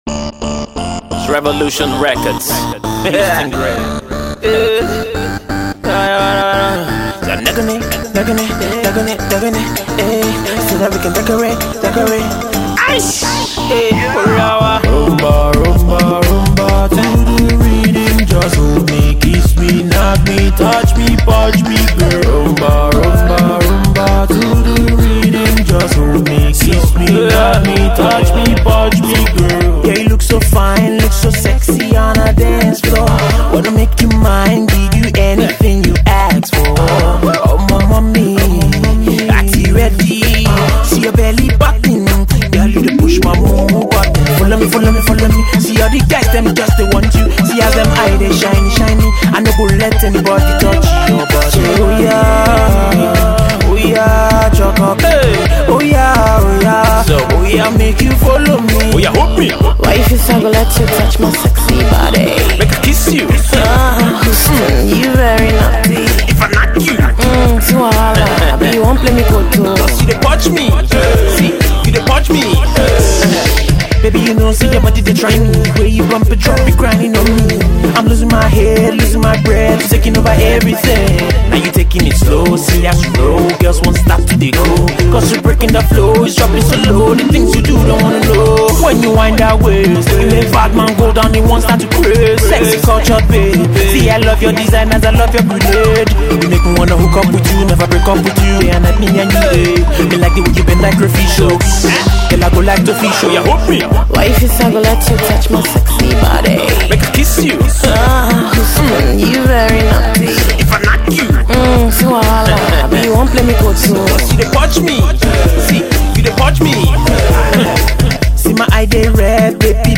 Afro Pop/Dance